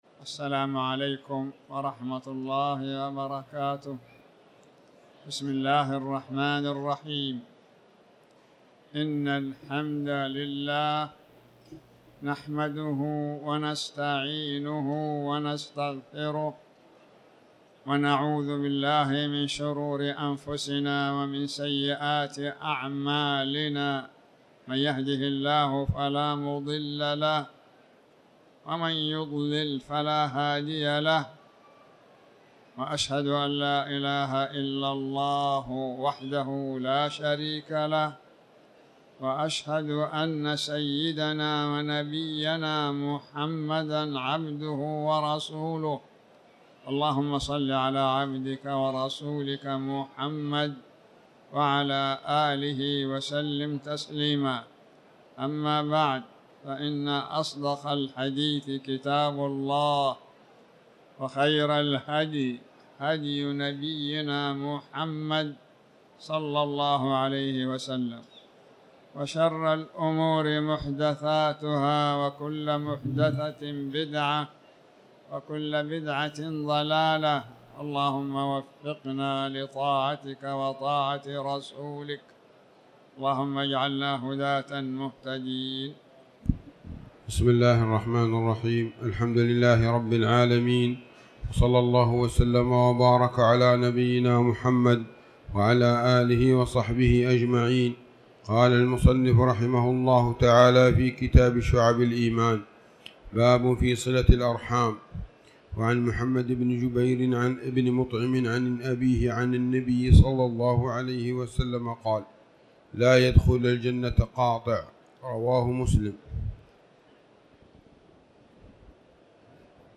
تاريخ النشر ١٤ جمادى الأولى ١٤٤٠ هـ المكان: المسجد الحرام الشيخ